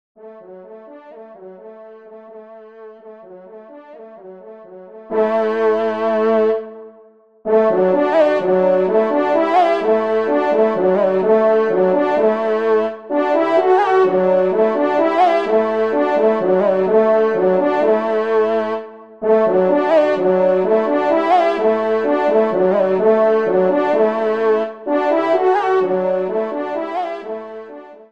TON DE VÈNERIE   :
Pupitre 2° Cor (en exergue)